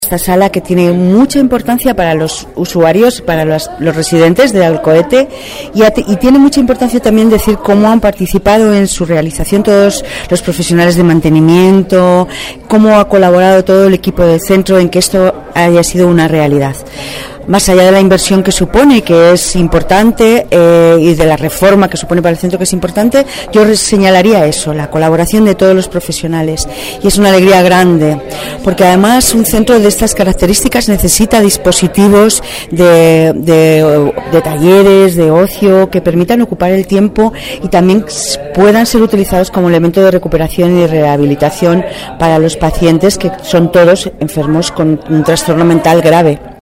Directora general de Planificación Sanitaria sobre inversión en URR Alcohete